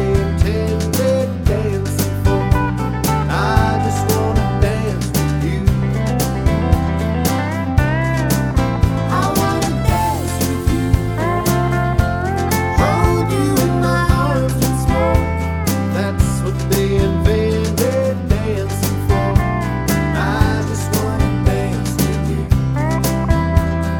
no Backing Vocals Country (Male) 3:18 Buy £1.50